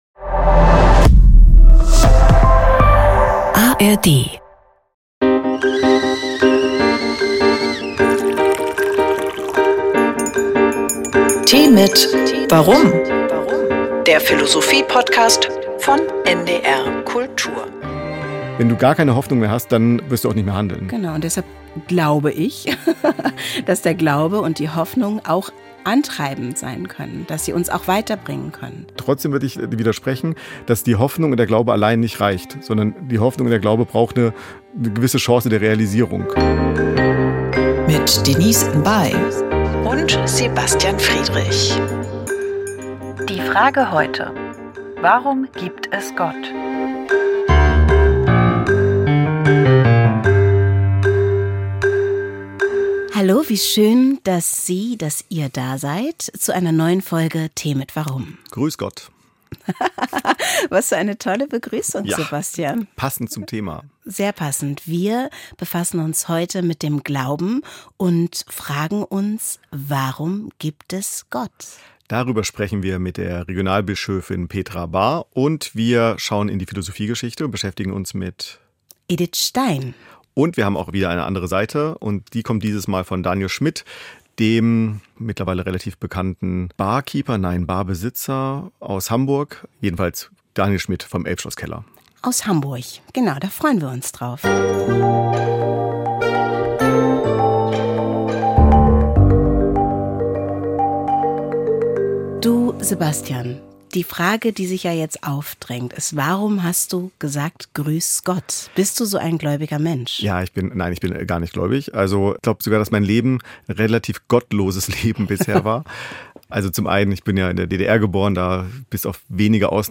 Die beiden diskutieren miteinander, schauen in die Philosophiegeschichte, sprechen mit Philosophinnen und Philosophen und hören Menschen aus dem Alltag.